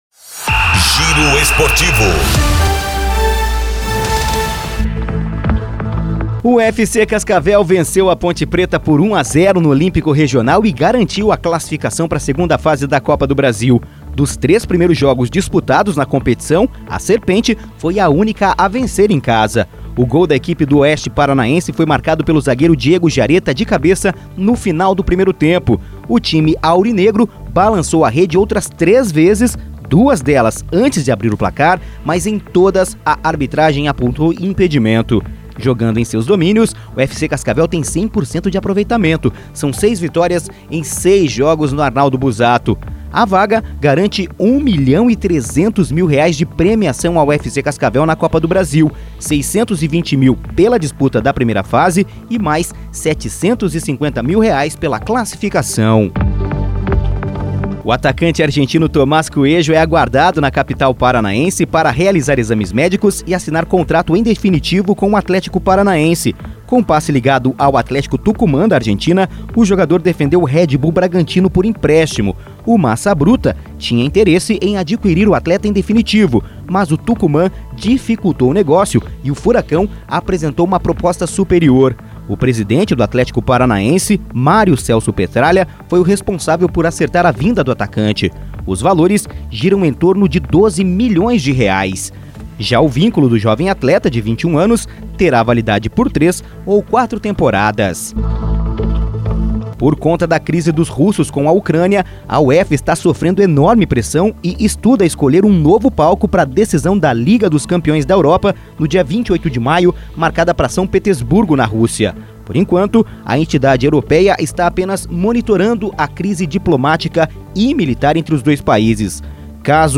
Giro Esportivo (COM TRILHA)